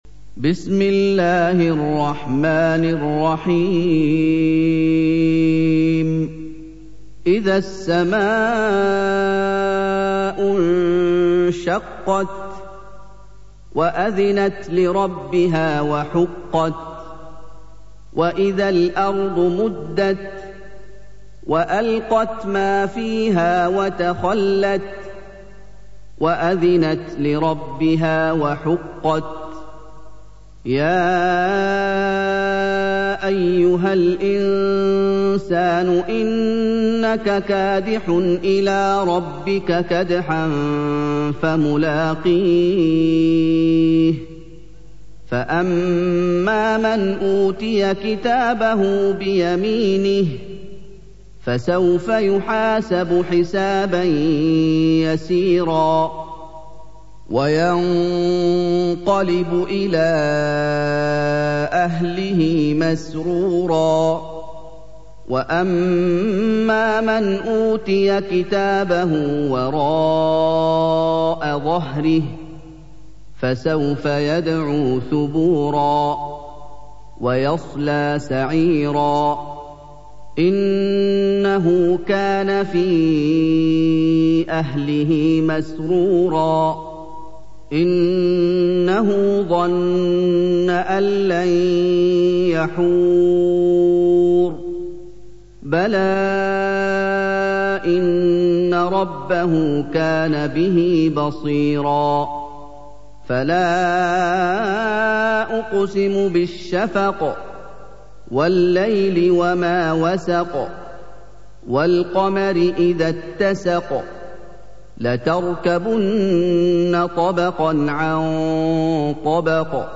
سُورَةُ الانشِقَاقِ بصوت الشيخ محمد ايوب